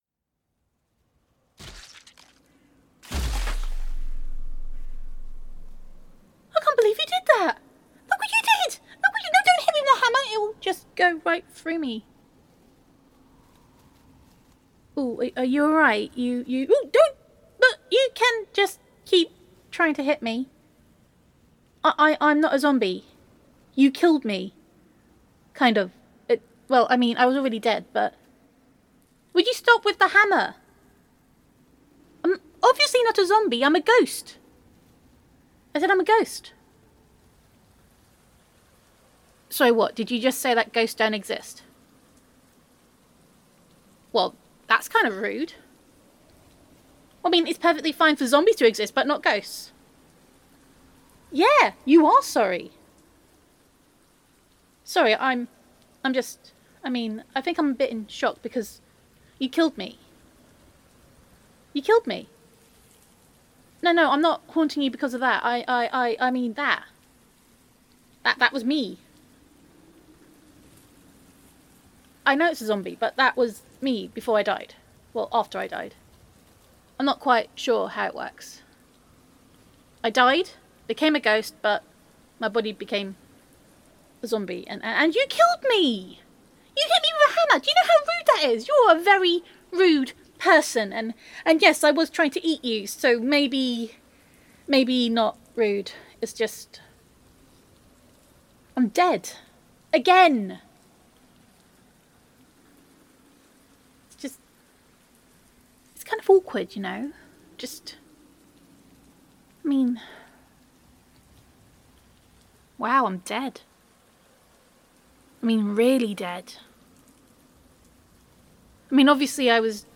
[F4A]
[Ghost Roleplay]
[Kettles Are Ghost ASMR]
[My Awesome Zombie Impression]